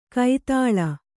♪ kai tāḷa